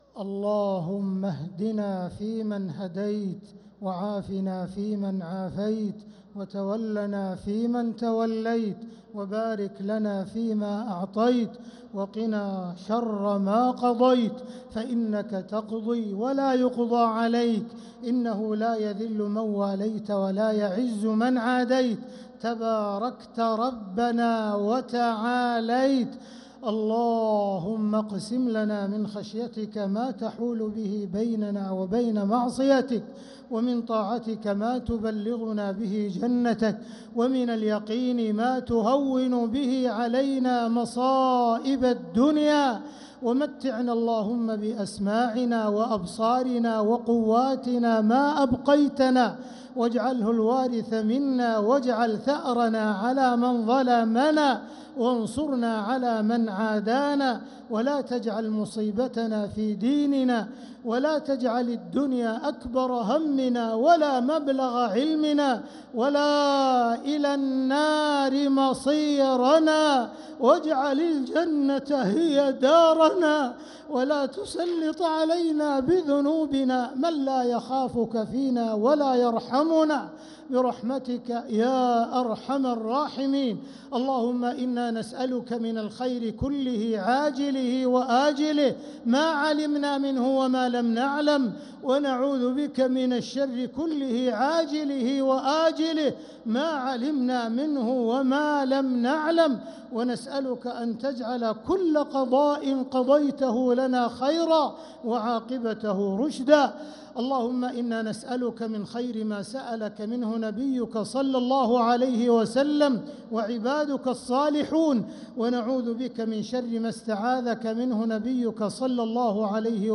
دعاء القنوت ليلة 13 رمضان 1446هـ | Dua 13th night Ramadan 1446H > تراويح الحرم المكي عام 1446 🕋 > التراويح - تلاوات الحرمين